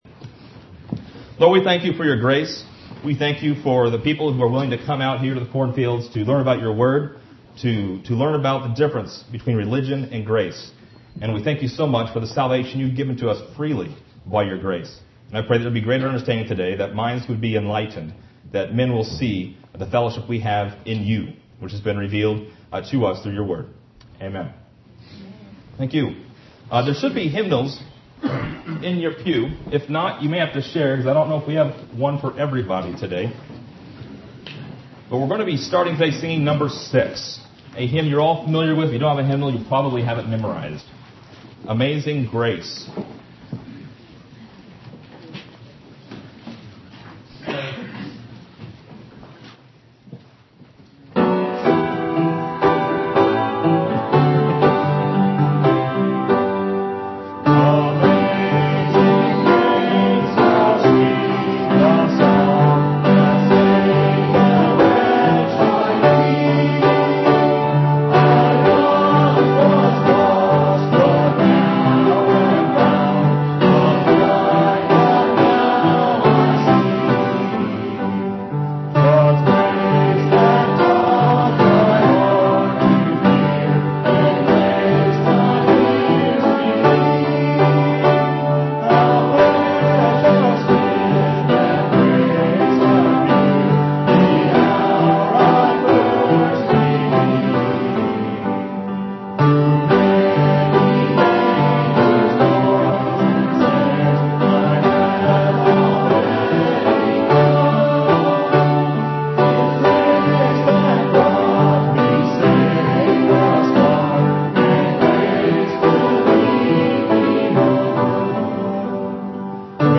Listen to more of the 2014 Ambassadors Seminar: Grace vs. Religion.